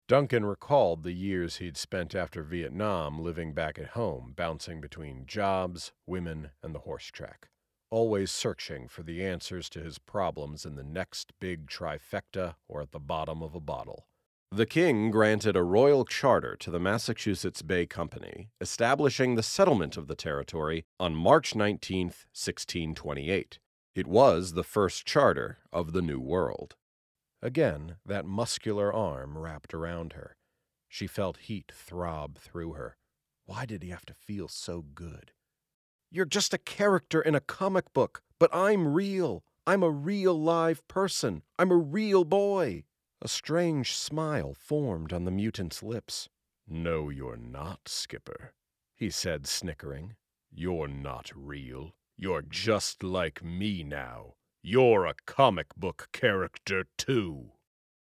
American Voice Over Talent
Adult (30-50)